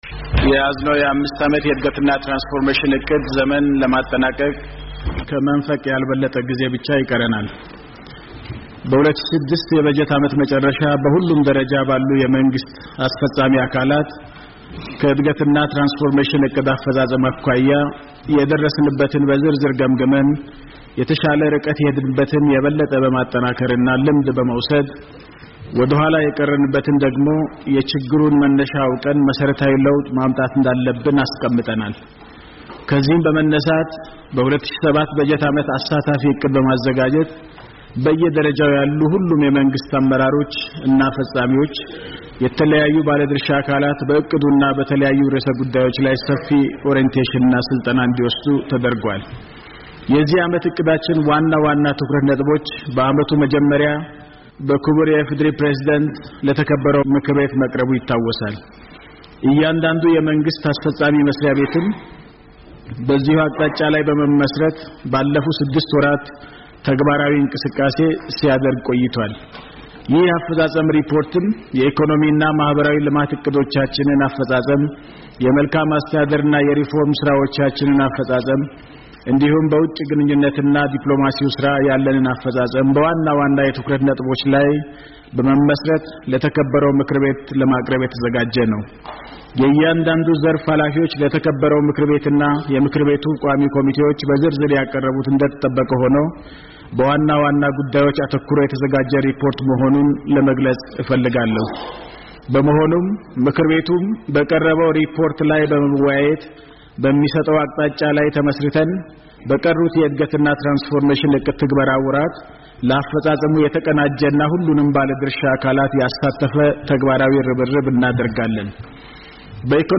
PM Hailemariam's full report to the Parliament - 02-06-15